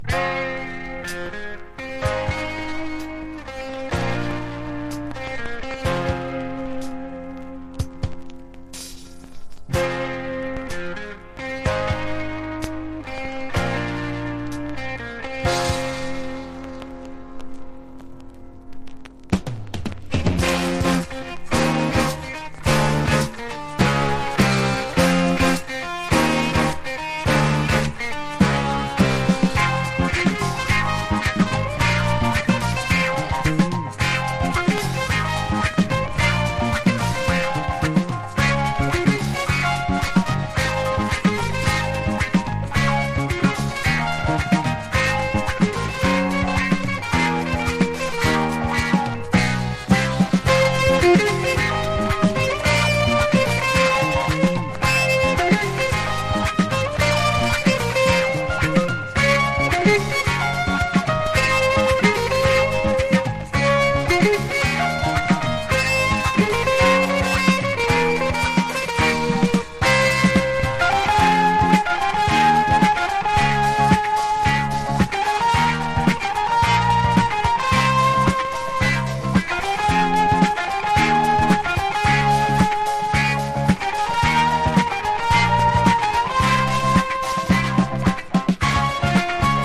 ポピュラー# SOUNDTRACK / MONDO